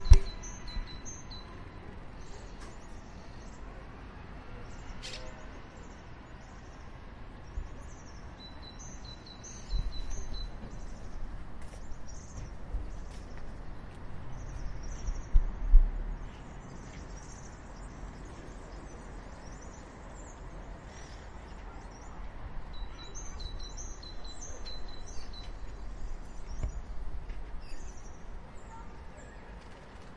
Sons d039;ocells al pati de l039;escola Galileo Galilei del Prat del Llobregat。